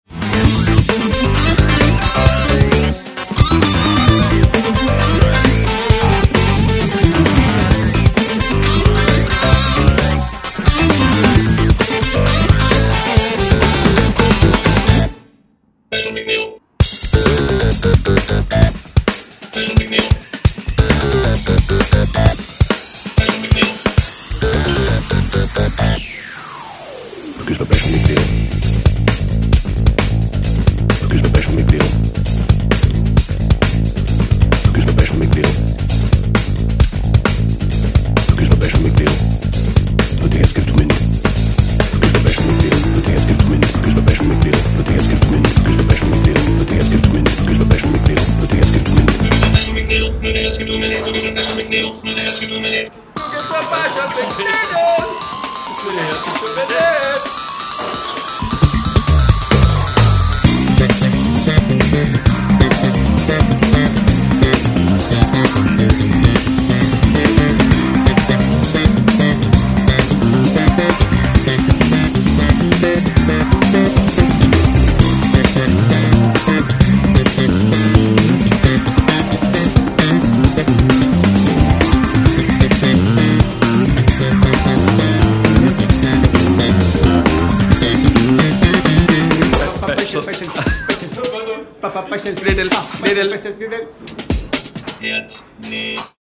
Piano, Keyboards & Programming
Basses
Guitars & Electric Sitar
Drums & Timbales
Percussion
Tenor, Soprano, Altosax & Flutes